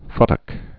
(fŭtək)